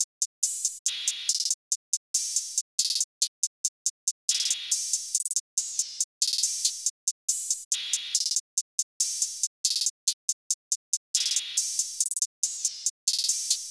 drumloop 3 (140 bpm).wav